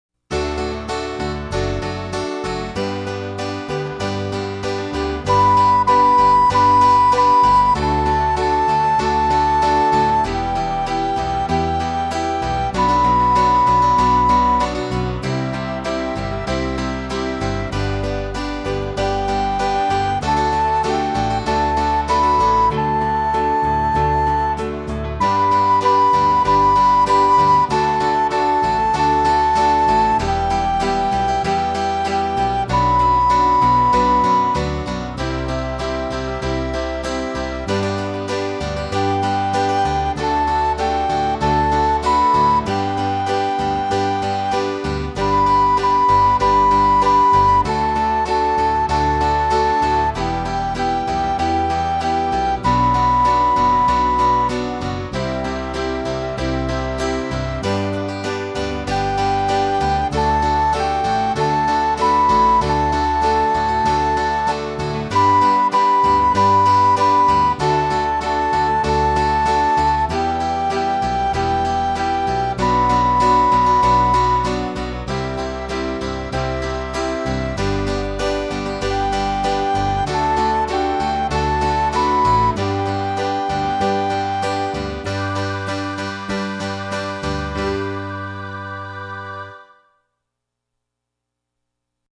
Voicing: Recorder Collection